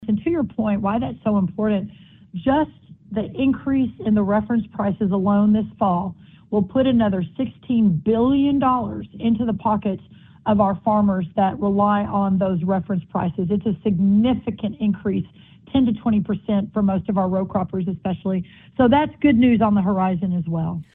In an interview with the Iowa Agribusiness Radio Network, U.S. Agriculture Secretary Brooke Rollins said those updates were included in the same H.R. 1 legislation passed last summer that also addressed tax policy affecting agriculture.